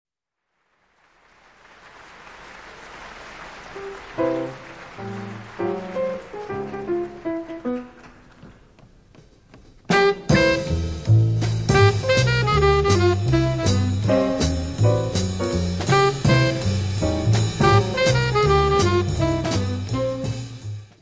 Área:  Jazz / Blues